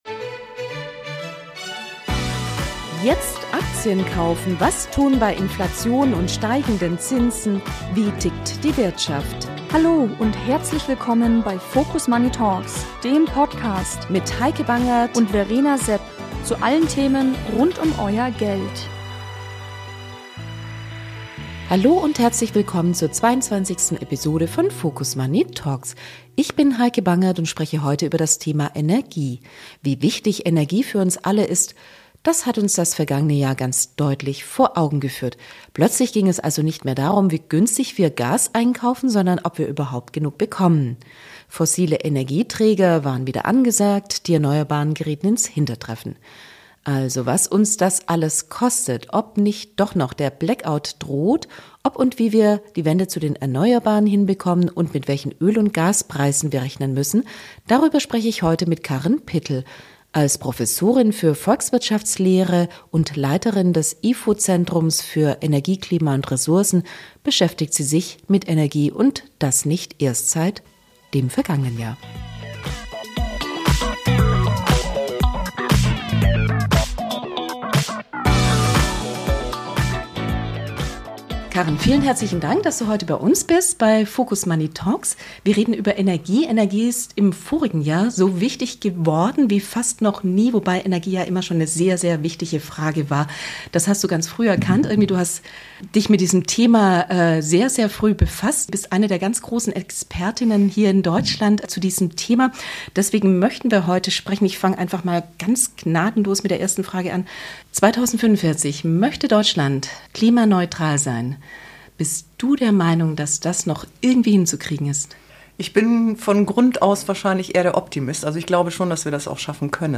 #22 Das Thema der Stunde: Energie. Interview